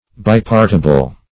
Search Result for " bipartible" : The Collaborative International Dictionary of English v.0.48: Bipartible \Bi*part"i*ble\, a. [Cf. F. bipartible.